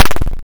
skeleton.wav